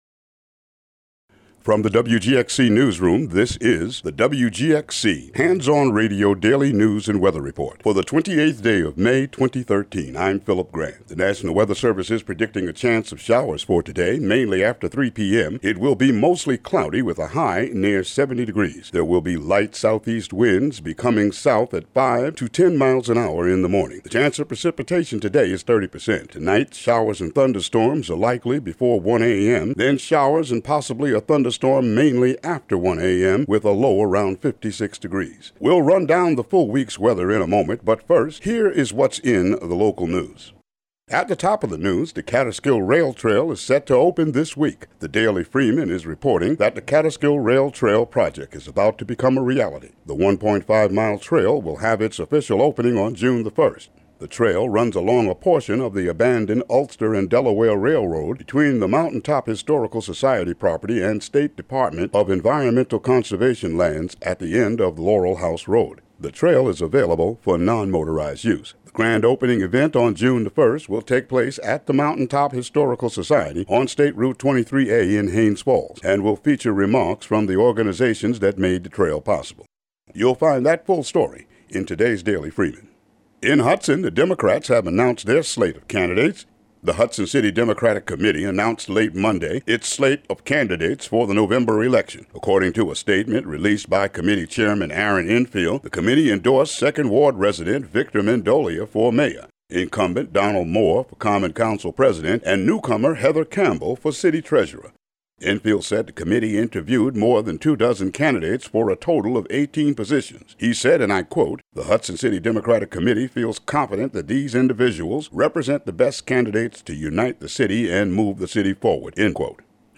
Local news and weather for Tuesday, May 28, 2013.